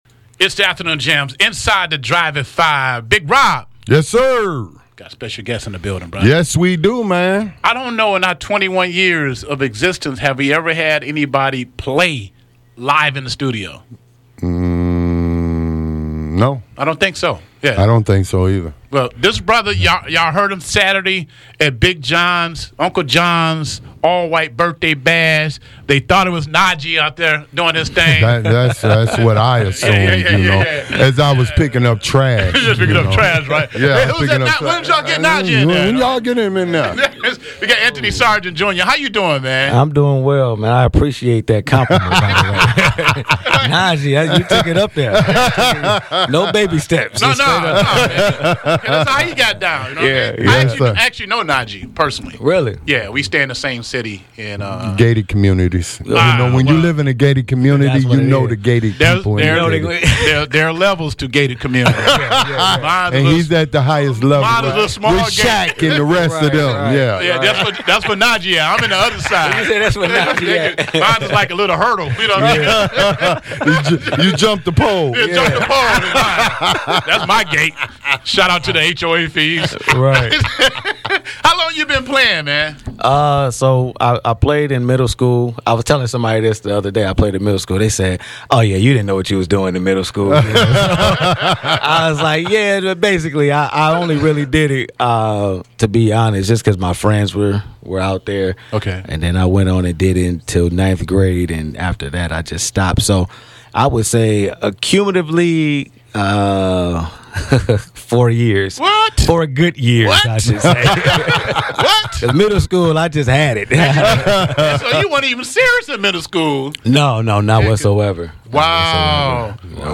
Saxophonist
Live on Radio
took to the airwaves to display his musical prowess.